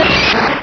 pokeemerald / sound / direct_sound_samples / cries / omanyte.aif
-Replaced the Gen. 1 to 3 cries with BW2 rips.